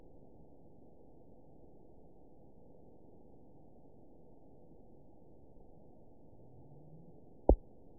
event 920920 date 04/16/24 time 01:35:43 GMT (1 year ago) score 5.32 location TSS-AB04 detected by nrw target species NRW annotations +NRW Spectrogram: Frequency (kHz) vs. Time (s) audio not available .wav